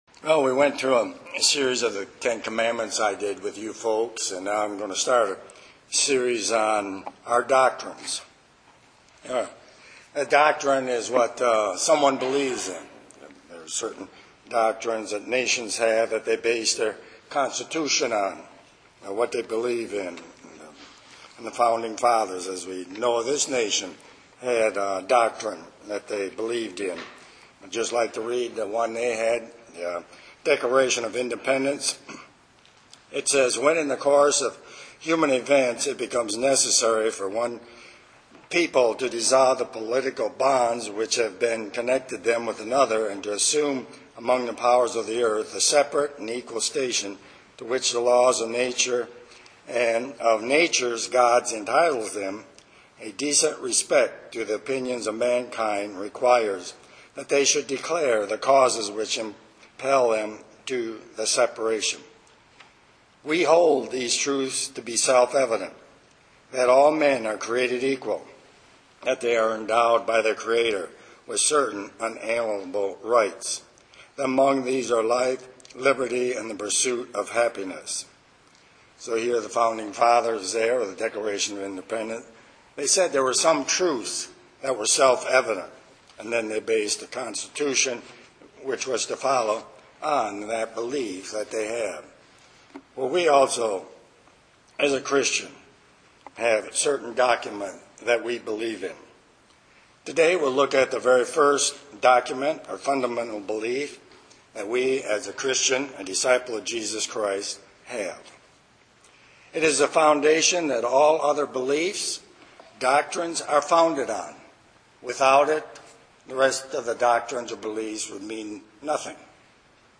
UCG Sermon Studying the bible?
Given in Ann Arbor, MI